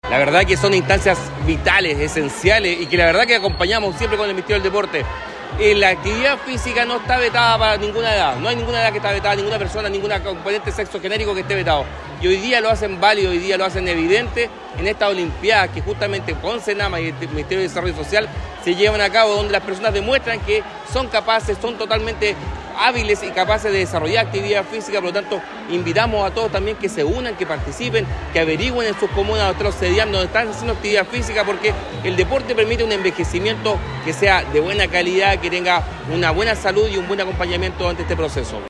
En tanto el Seremi del Deporte, Cristián Cartes indicó que “la actividad física no está vetada para ninguna edad y hoy día lo hemos visto plasmado con evidencia en estas Olimpiadas que organiza Senama y el Ministerio del Desarrollo Social”.
cuna-03-olimpiadas-adultos-mayores.mp3